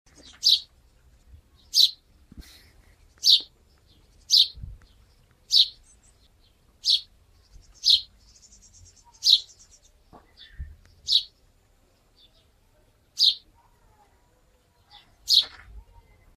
Звуки воробья
На этой странице собраны разнообразные звуки воробьёв: от одиночного чириканья до оживлённого щебетания стаи.